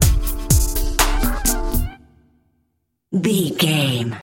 Aeolian/Minor
synthesiser
drum machine